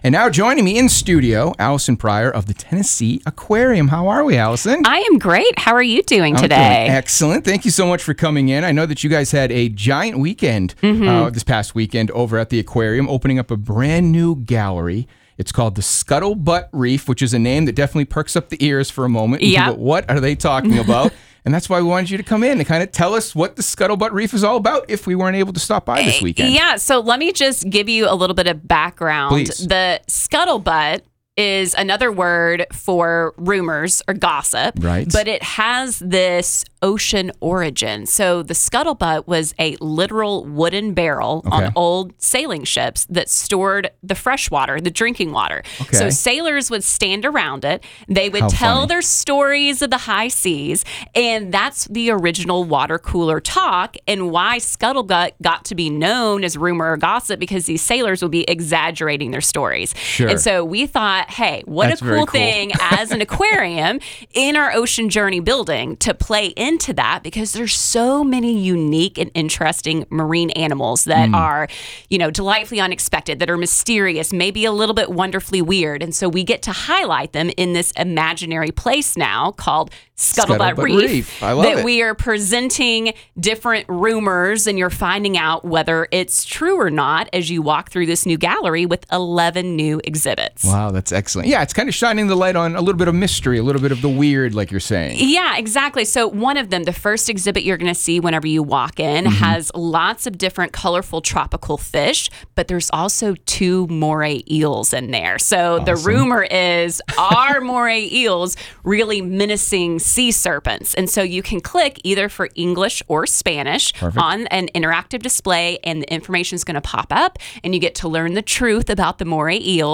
Interview.wav